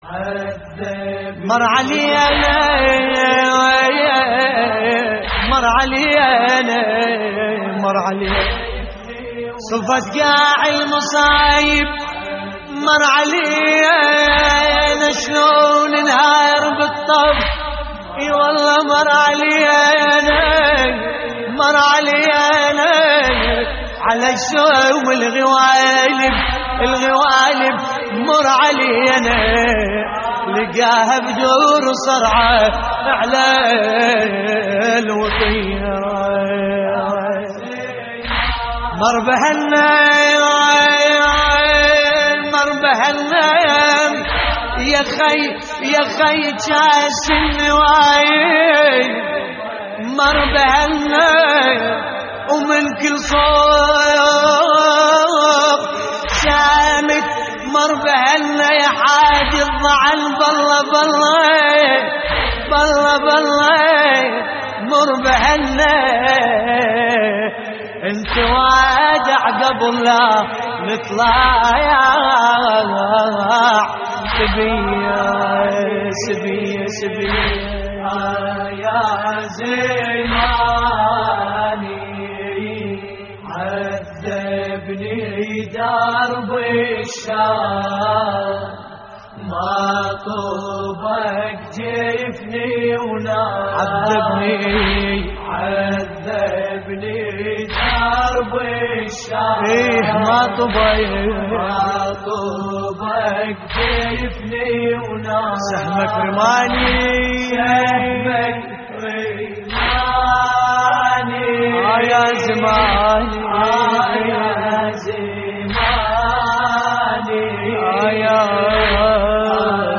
موقع يا حسين : اللطميات الحسينية عذبني درب الشام ما طبق جفني ونام - استديو لحفظ الملف في مجلد خاص اضغط بالزر الأيمن هنا ثم اختر (حفظ الهدف باسم - Save Target As) واختر المكان المناسب